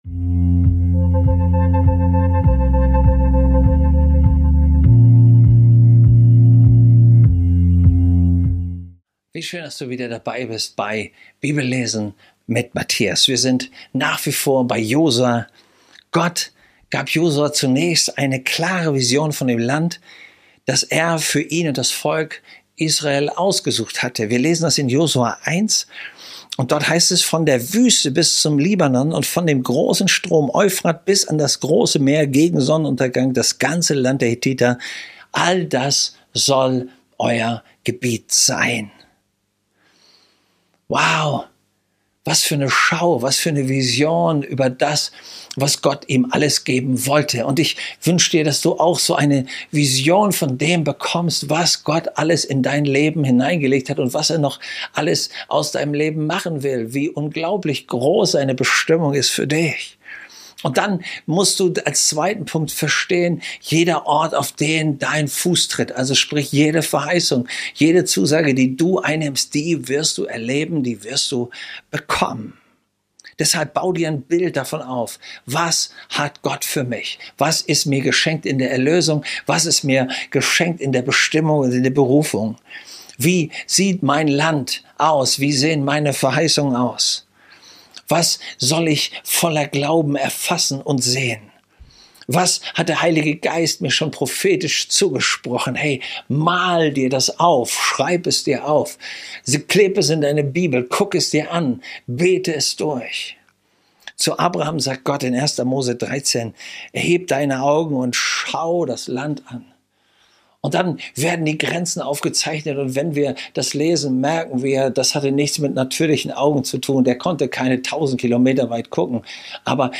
Bibellesen